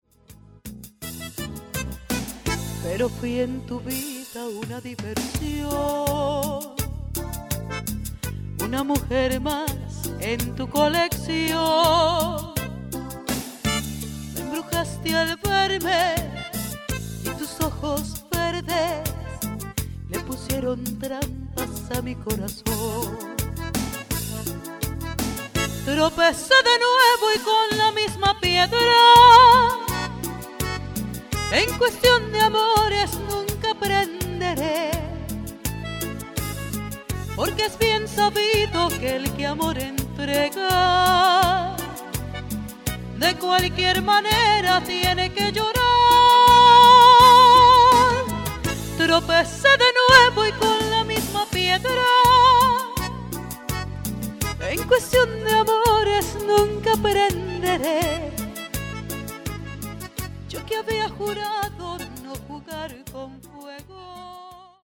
Latin Music